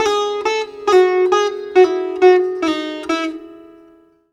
SITAR LINE24.wav